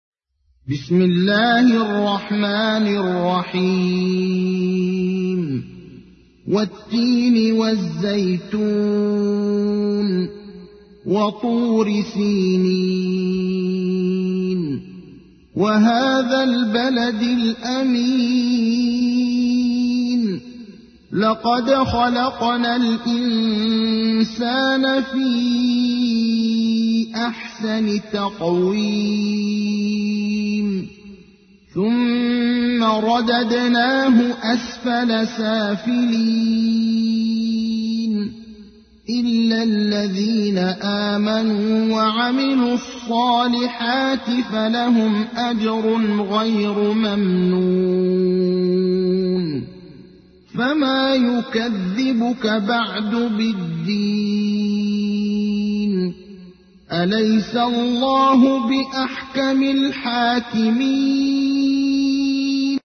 تحميل : 95. سورة التين / القارئ ابراهيم الأخضر / القرآن الكريم / موقع يا حسين